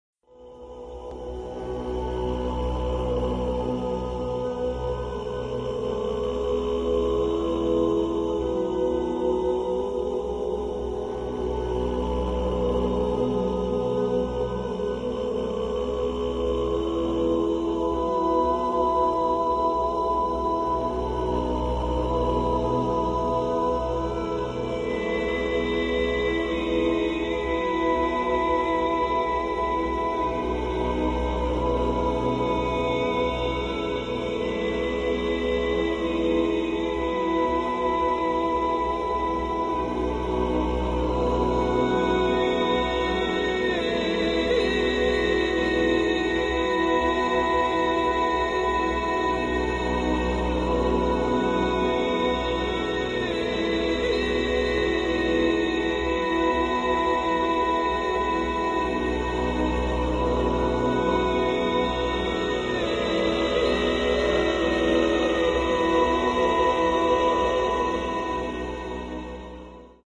trumpet, voice, keyboards and electronics
keyboards
guitars and bow